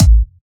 Kick 1 (First Of the year).wav